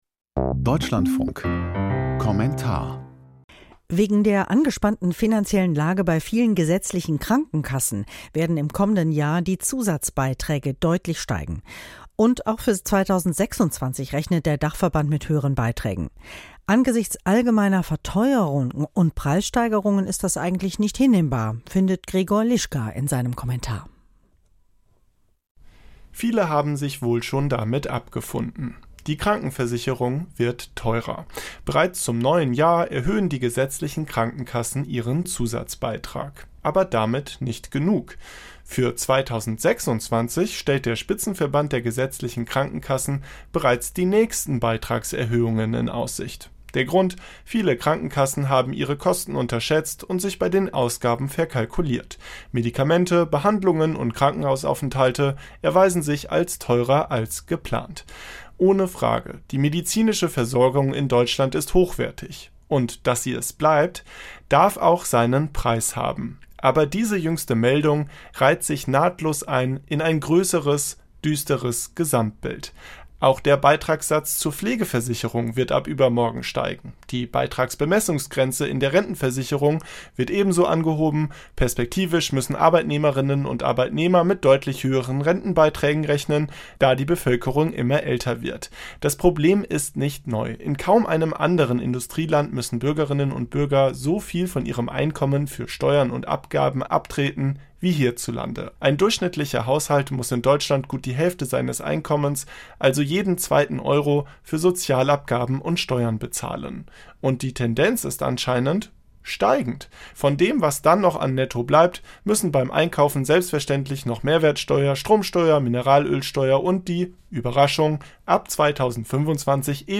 Kommentar zur Beitragserhöhung der Krankenkassen